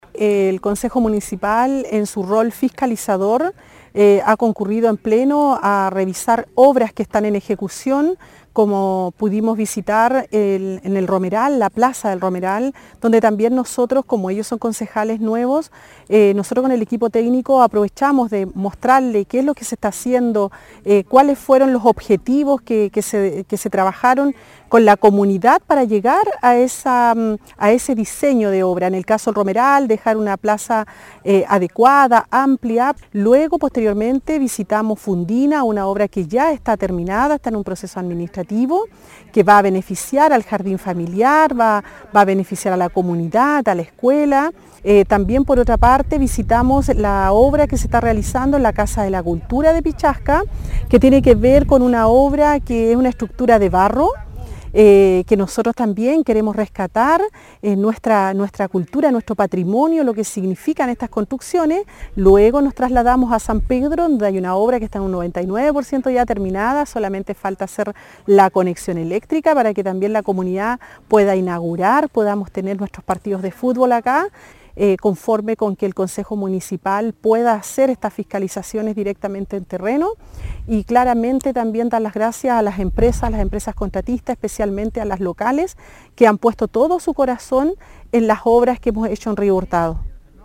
La alcaldesa Carmen Juana Olivares valoró el trabajo realizado en beneficio de las comunidades de Río Hurtado.